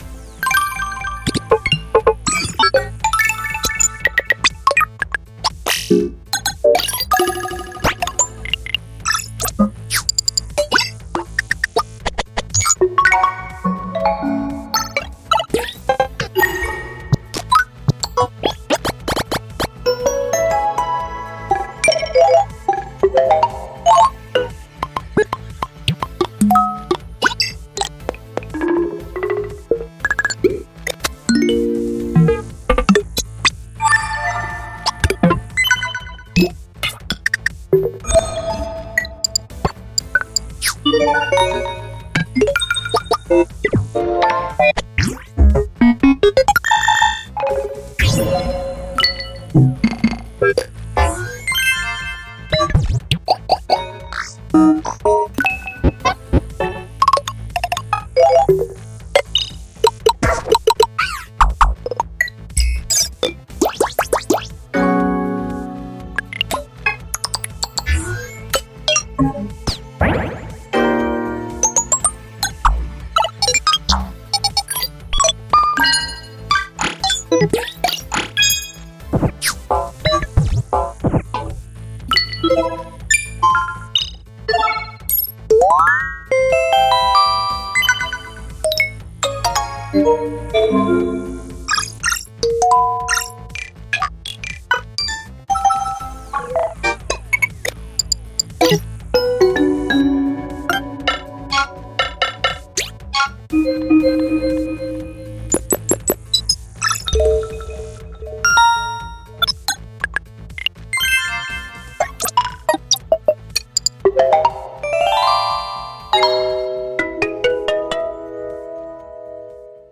406个休闲游戏UI界面音效素材 Epic Stock Media Hyper Casual Game UI
提供了406个游戏界面UI音效素材，如：按钮音、通知音、成就音、选择音等。
声道数：立体声